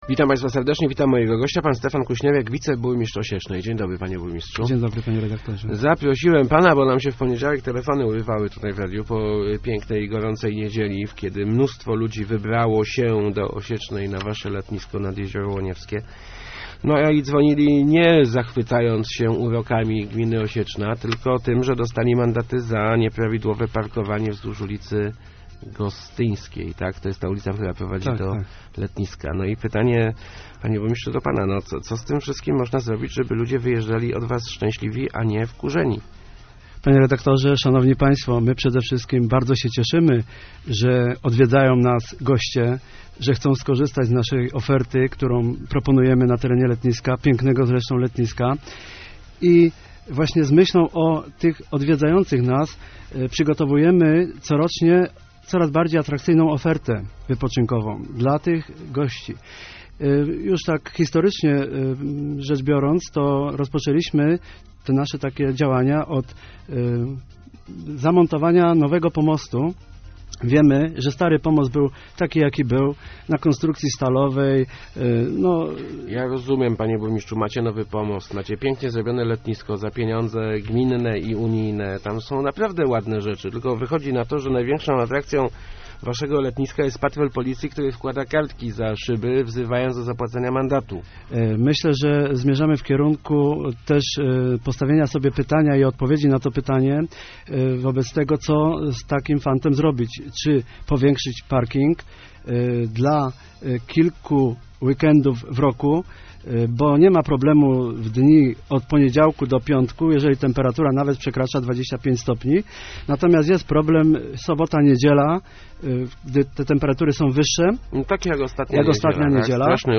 skusnierek80.jpgRozbudowa parkingu na letnisku jest niemożliwa z przyczyn ekonomicznych - mówił w Rozmowach Elki wiceburmistrz Osiecznej Stefan Kuśnierek.